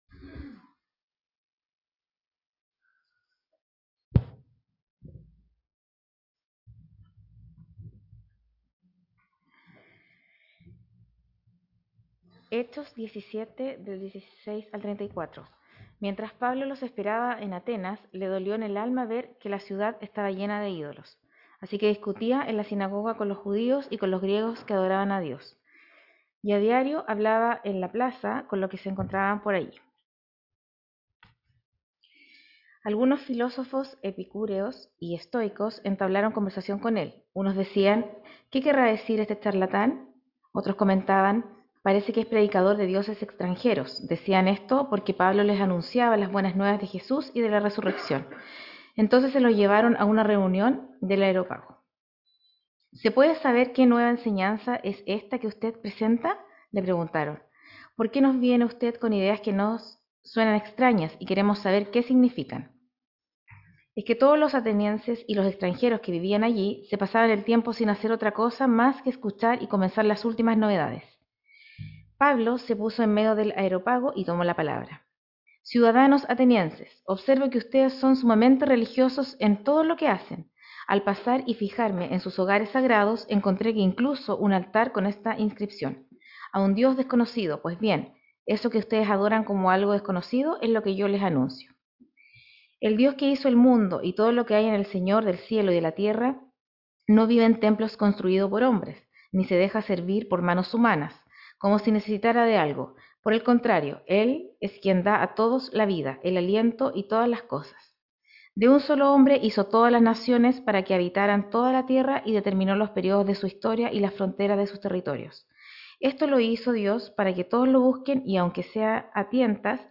Sermones
Website de la Iglesia St. James de Punta Arenas Chile